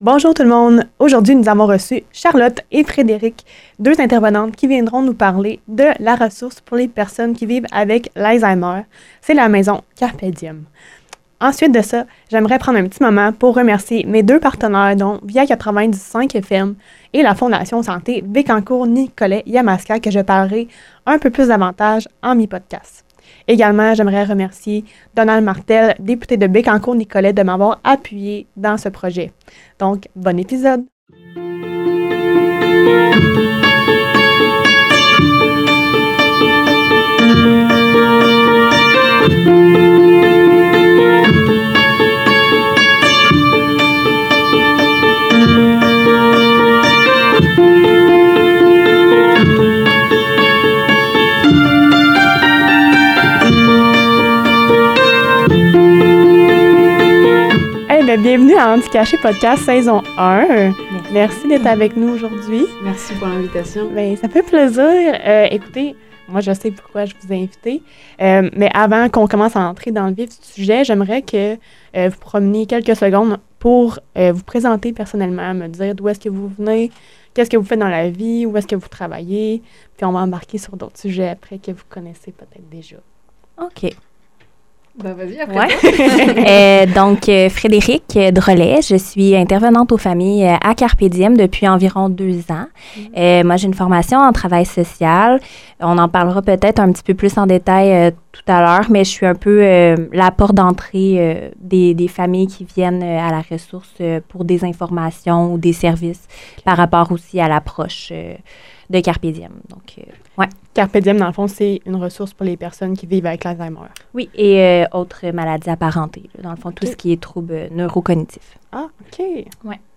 Un échange qui nous invite à voir au-delà du diagnostic pour reconnaître la personne dans toute sa richesse.